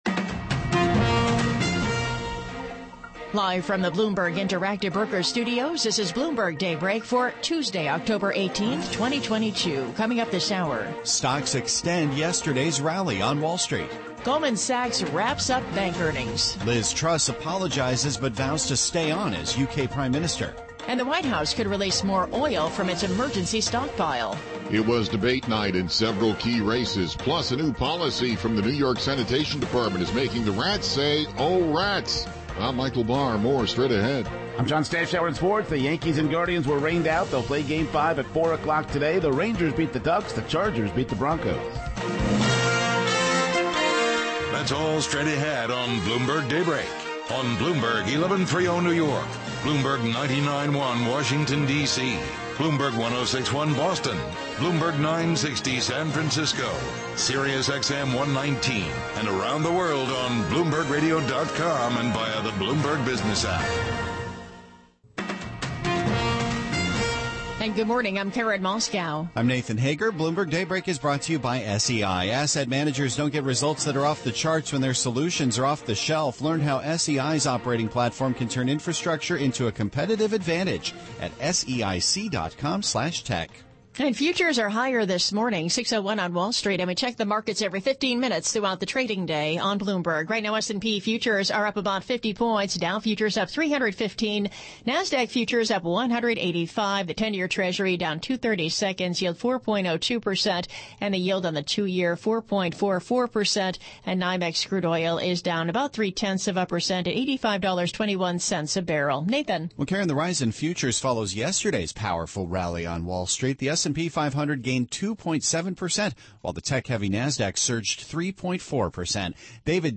Bloomberg Daybreak: October 18, 2022 - Hour 2 (Radio)